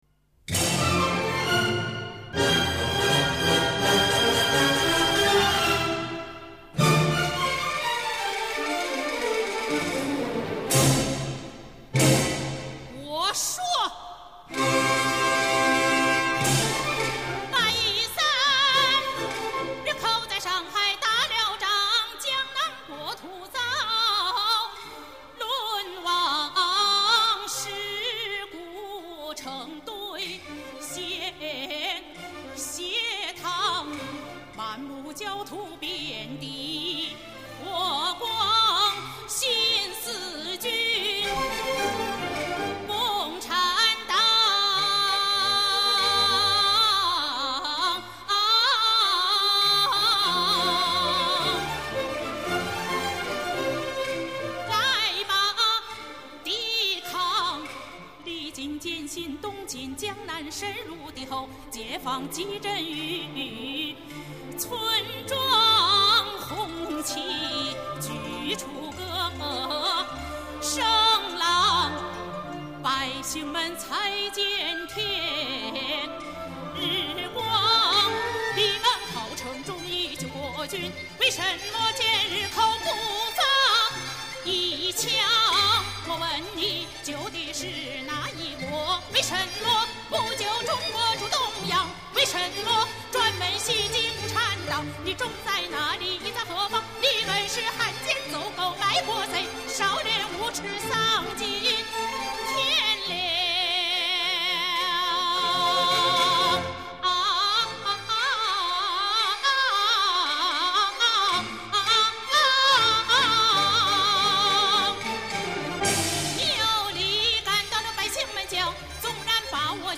由传统京剧音乐中的京胡、二胡、打击乐加西洋管弦乐队，再加上部分民族乐器为之伴奏。
运用了大型西洋乐队、声乐独唱及交响合唱的歌唱唱法